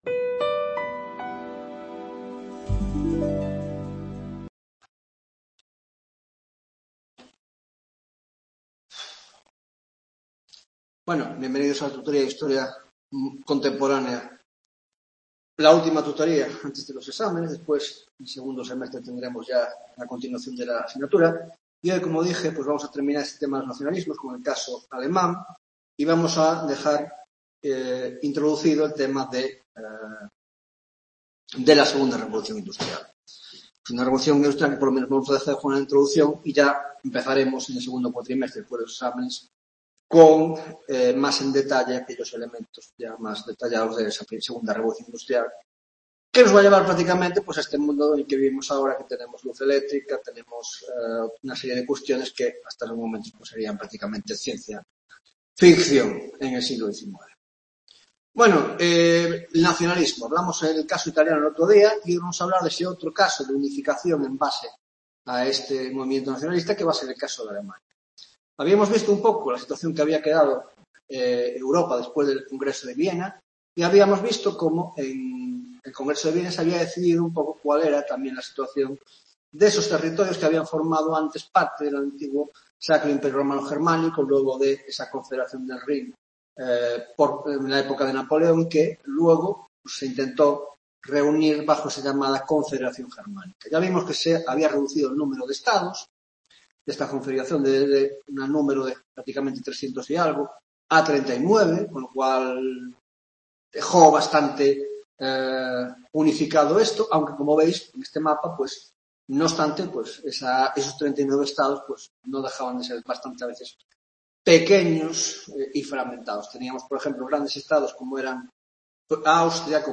11 Tutoría de Historia Contemporánea